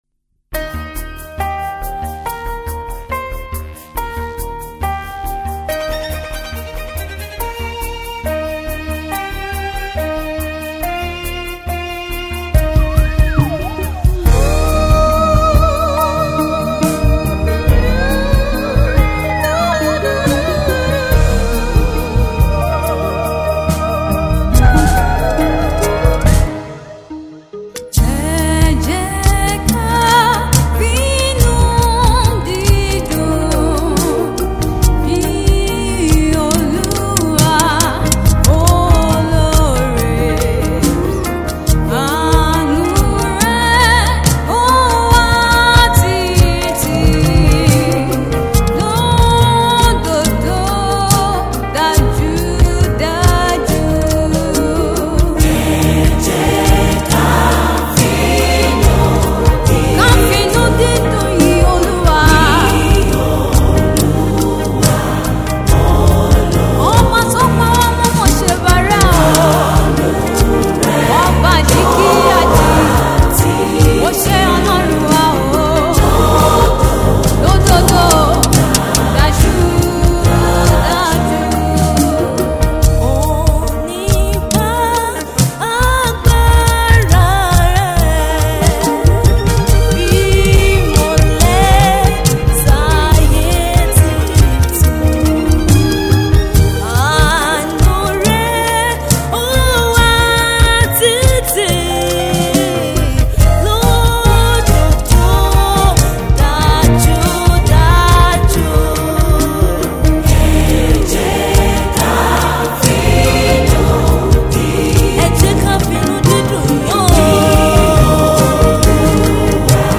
Gospel
In the back-ups is the echoed celestial voices
the violin
Midrange Mastering Suite, London.